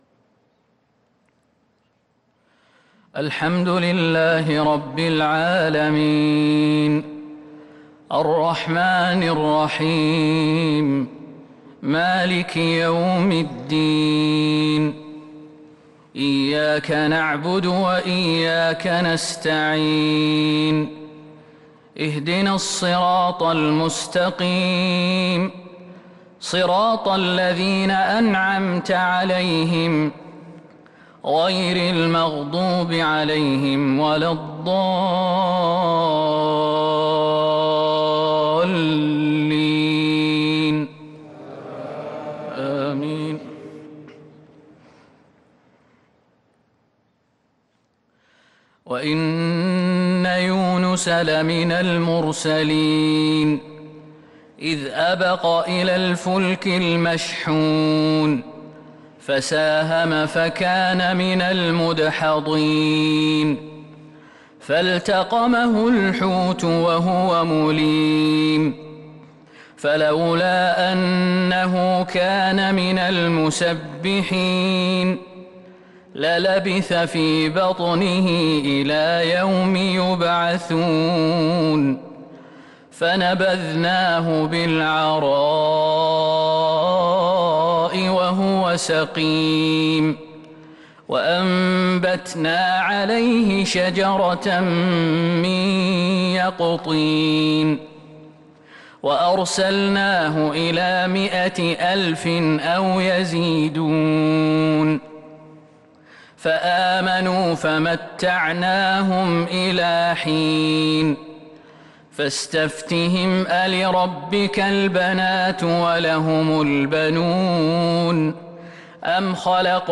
صلاة العشاء للقارئ خالد المهنا 17 جمادي الأول 1443 هـ
تِلَاوَات الْحَرَمَيْن .